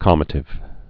(kämə-tĭv, kălmə-)